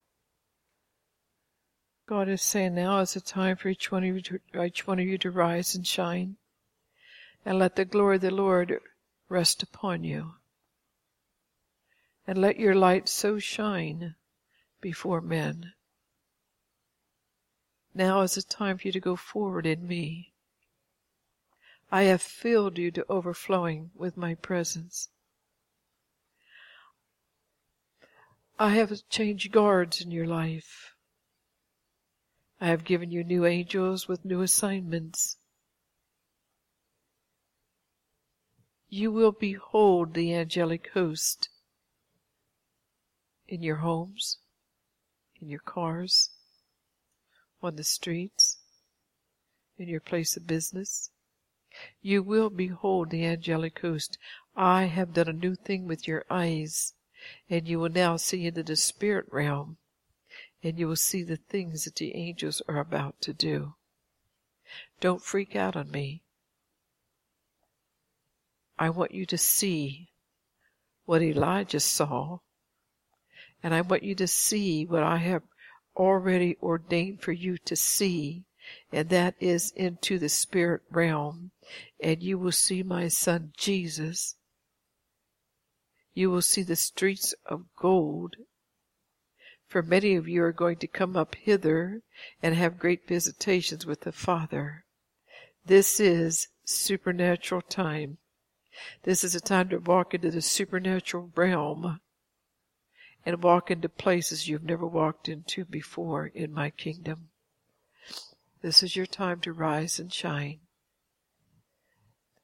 Today’s Fresh Word are two audio prophetic word’s. (Please click link or go to our website to listen)